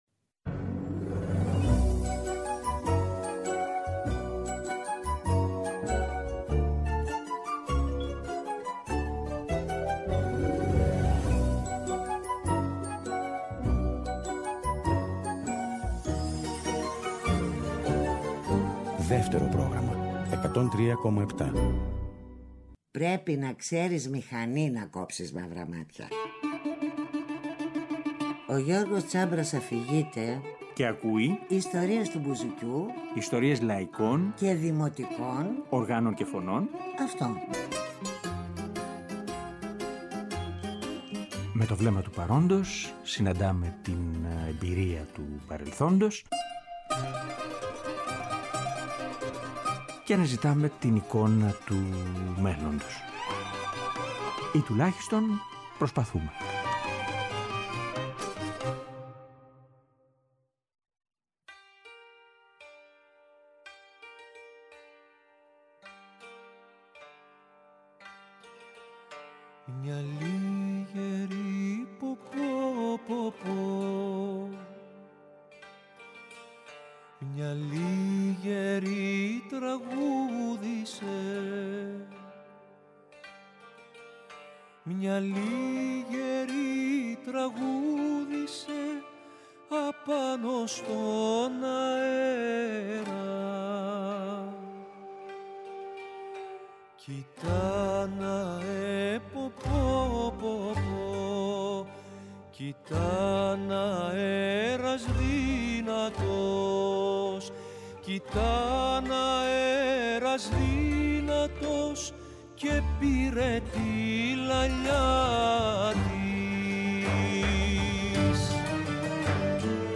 Τετάρτη 6 Οκτωβρίου, 9 με 10 το βράδυ, στο Δεύτερο Πρόγραμμα 103.7. Η εκπομπή με τις επιλογές και τη σκέψη της, θέτει το ζήτημα της παρουσίας του δημοτικού τραγουδιού σήμερα.